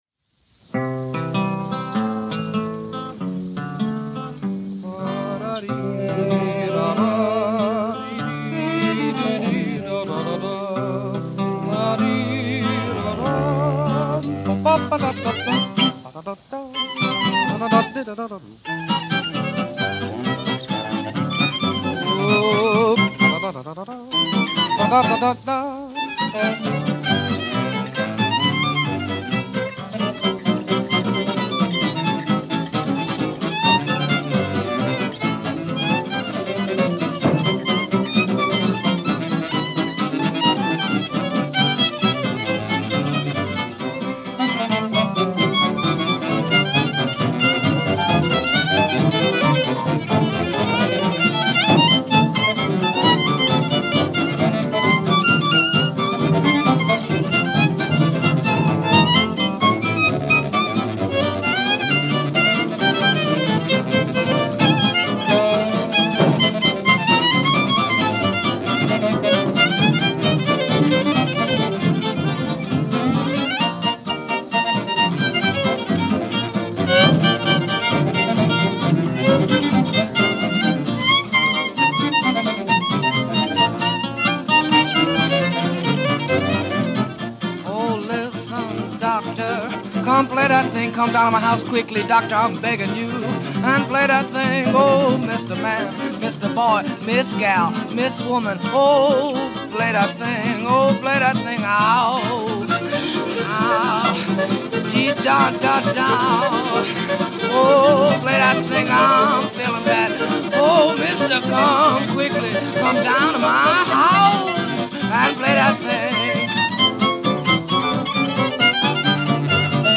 Extraits de la bande originale :
effectivement sensationnel et au violon déjà très manouche.